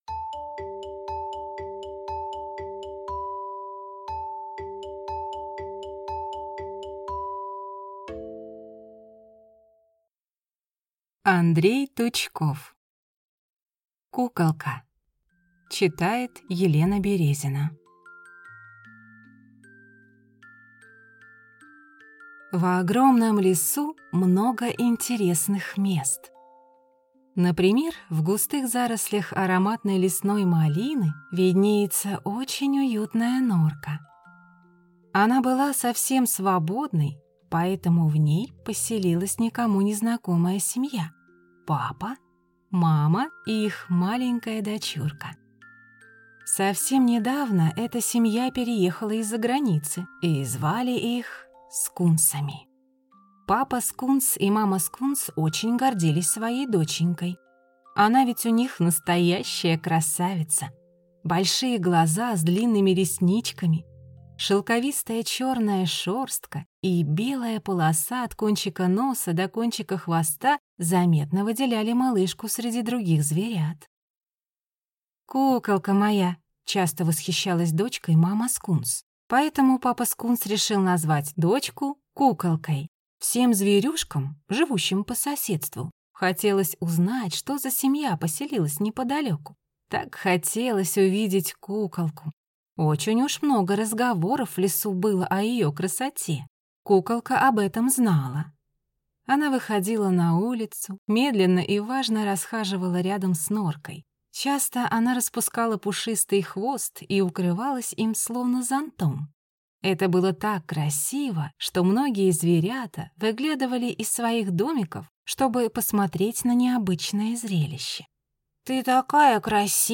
Куколка - аудиосказка Тучкова - слушать онлайн